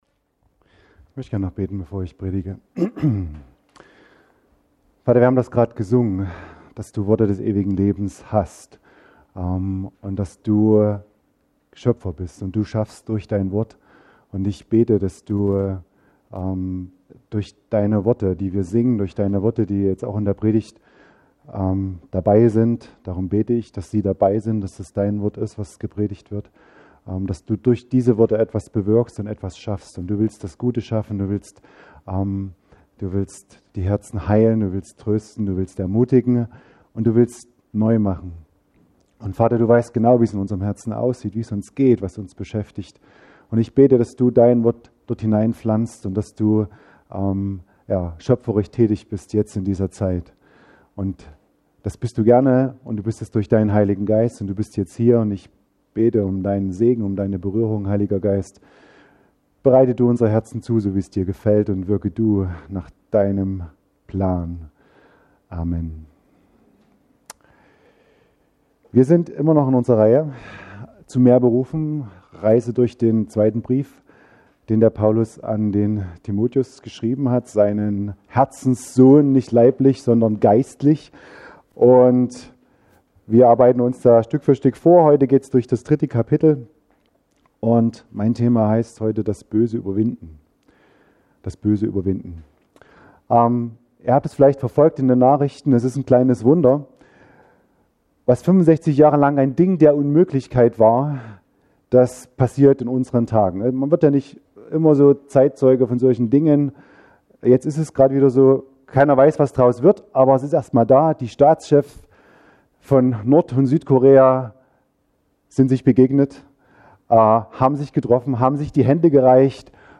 Das Böse überwinden – Freie evangelische Gemeinde Brandis